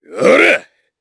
Chase-Vox_Attack1_jp.wav